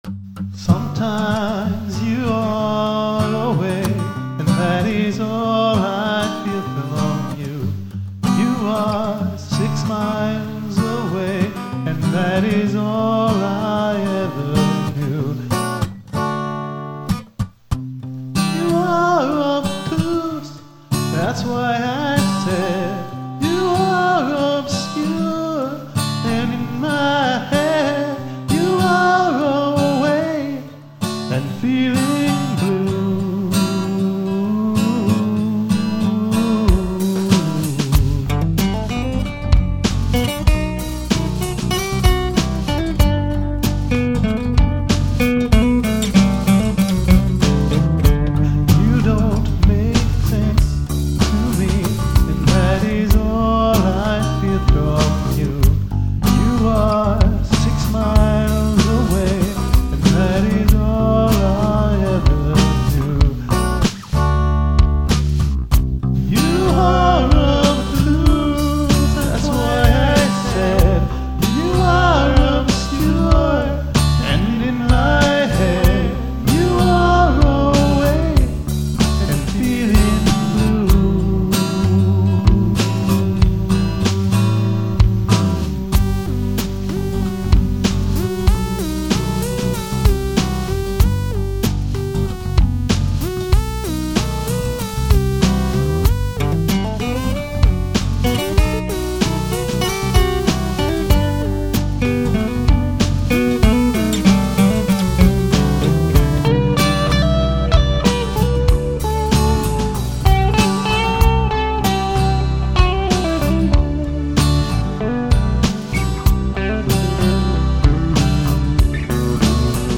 I'm not too happy with the vocals - some general off-pitchiness and especially the hesitant entrance on the first chorus ; however, mic and other technical issues made all but the first take unusable.
The acoustic jazziness is shmexy, and the synth breakdown made me smile.